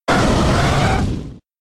Cri de Galopa K.O. dans Pokémon X et Y.